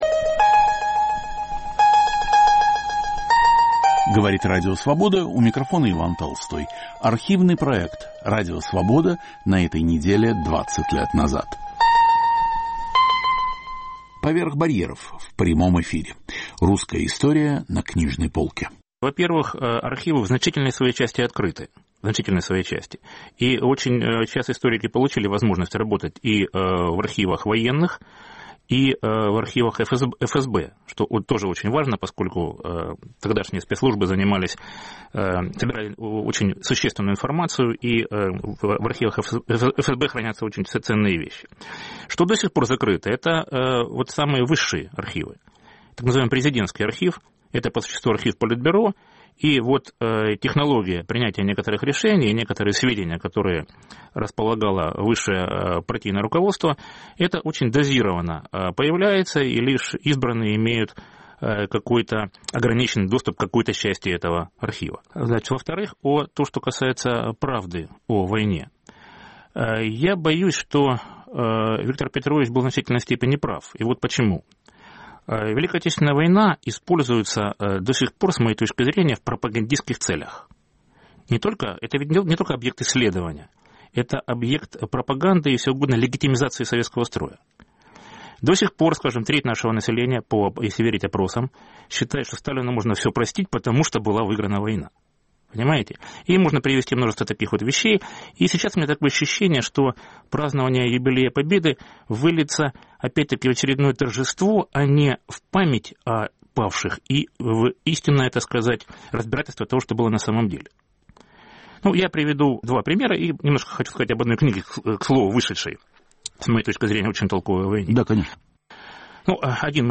"Поверх барьеров" в прямом эфире. Русская история на книжной полке